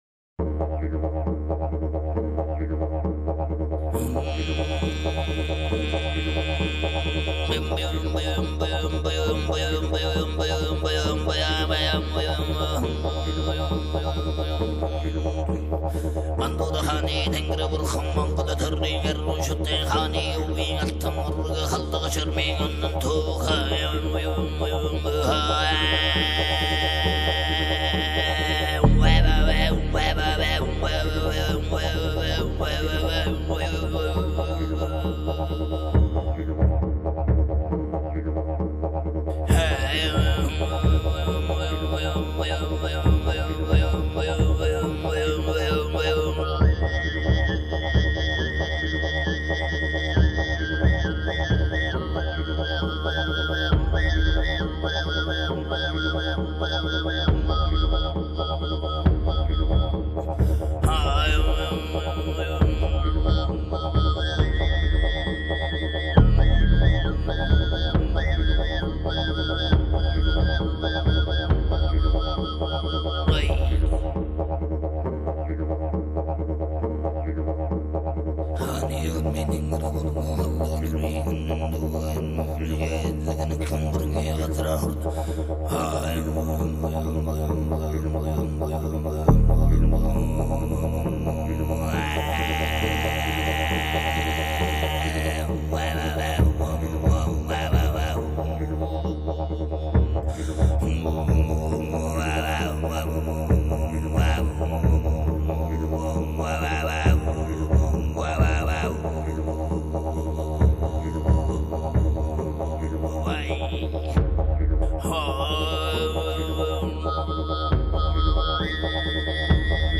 声音技巧，一人同时唱出两个声部，形成罕见的多声部形态。
喉头唱歌("呼麦")和合成器,小提琴等乐器完美的编织在